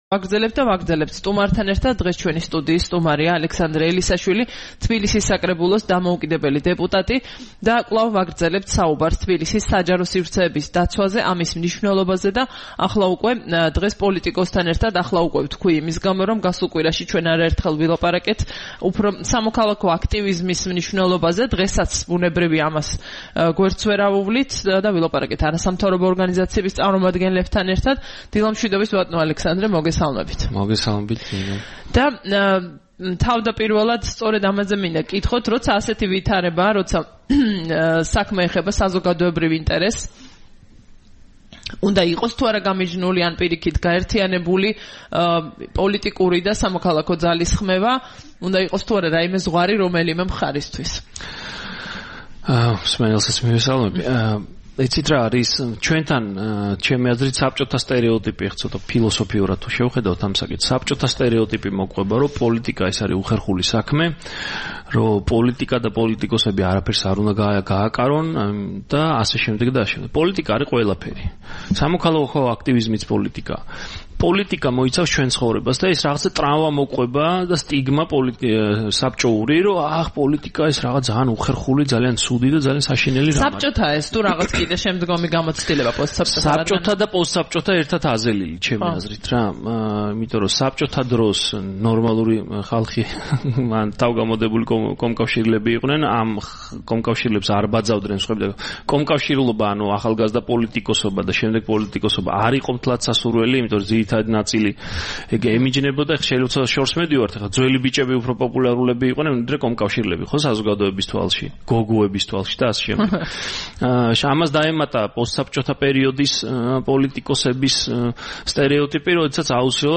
სტუმრად ჩვენს ეთერში: ალექსანდრე ელისაშვილი
9 მაისს რადიო თავისუფლების "დილის საუბრების" სტუმარი იყო ალექსანდრე ელისაშვილი, თბილისის საკრებულოს დამოუკიდებელი დეპუტატი.